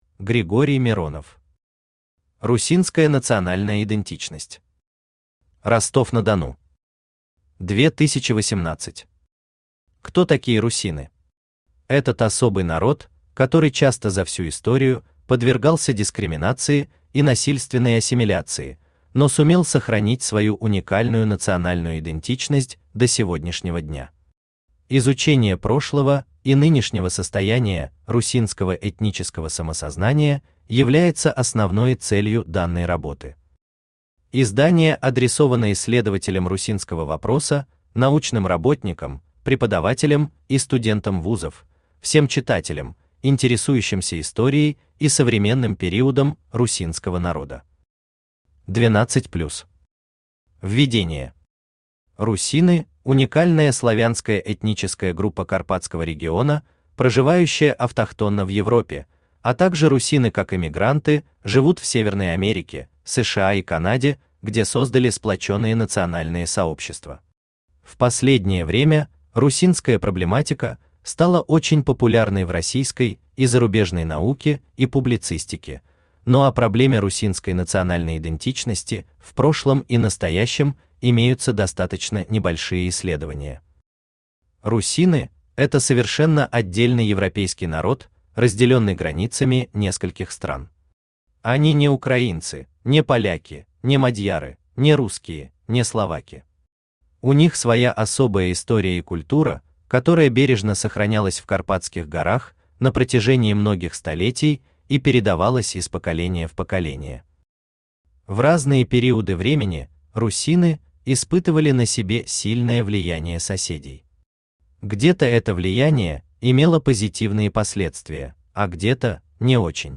Aудиокнига Русинская национальная идентичность.
Автор Григорий Юрьевич Миронов Читает аудиокнигу Авточтец ЛитРес.